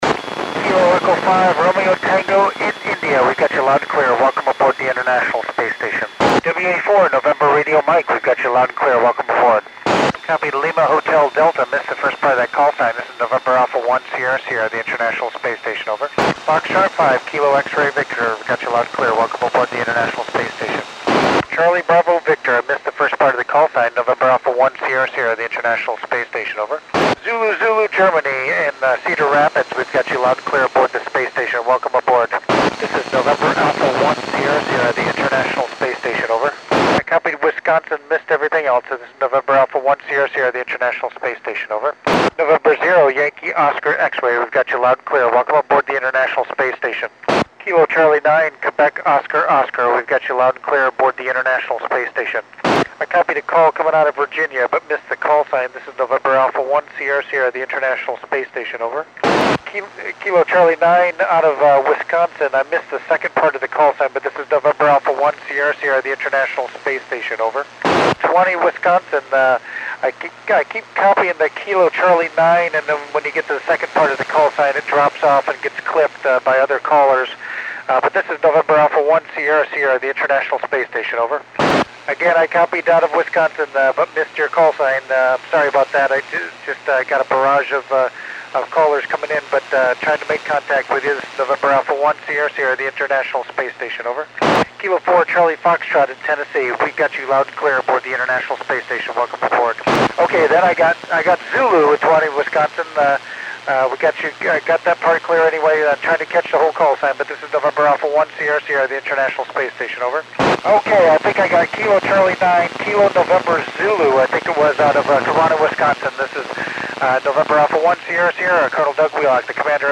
NA1SS (Col. Doug Wheelock) working U.S. stations on 04 November 2010 at 2129 UTC